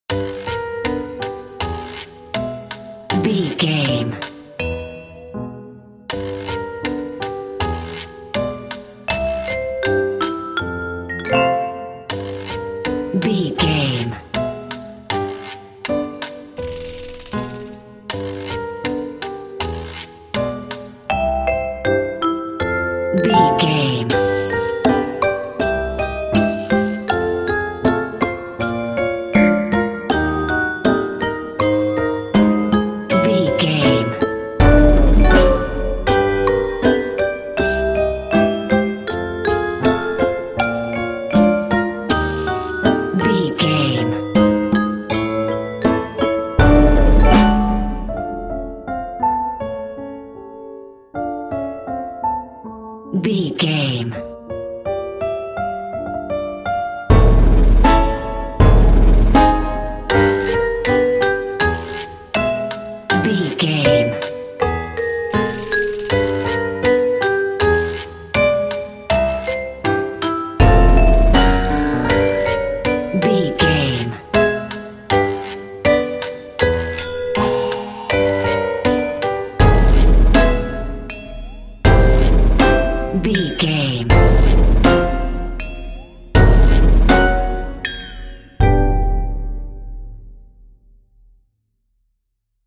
Ionian/Major
piano
percussion
silly
goofy
comical
cheerful
perky
Light hearted
quirky